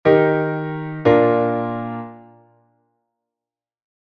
Cadencia Plagal: IV-I
MI-MI-SOL-SI; SI-RE-FA-SI